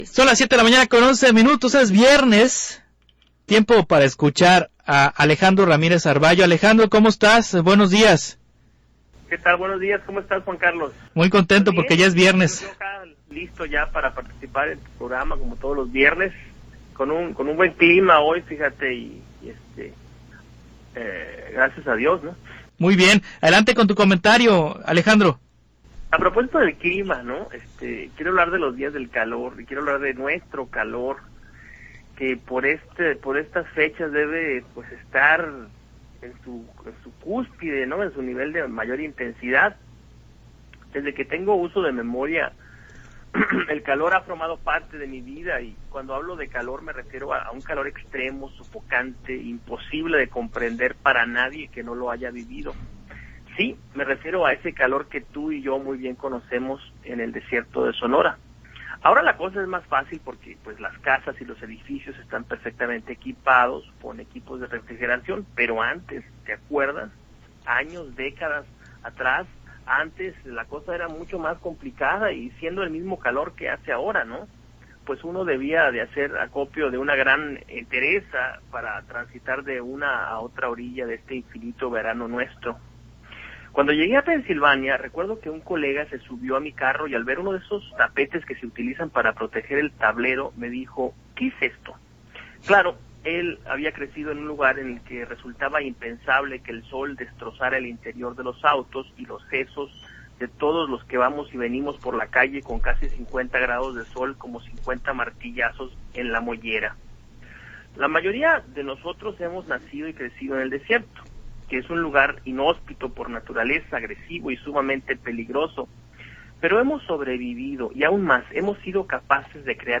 Transmisión en radio